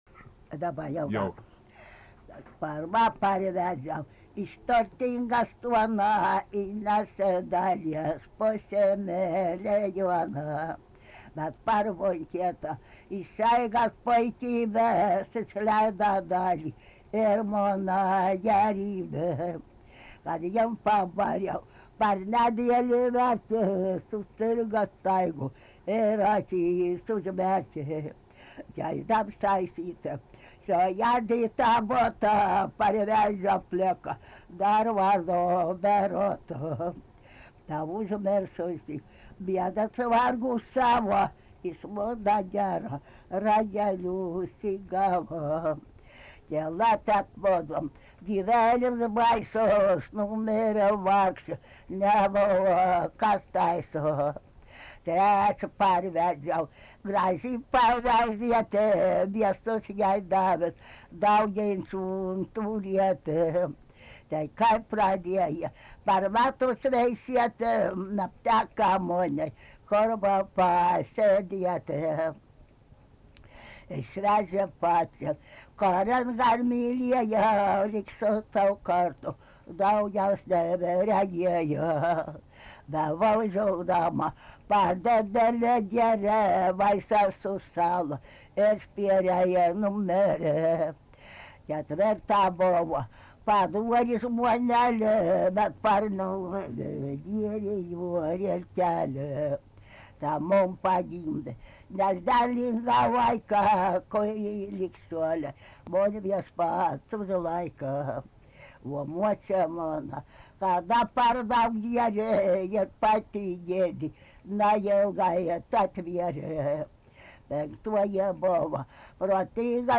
Plungė
vokalinis
Du kartus pradeda. Pabaiga nukirpta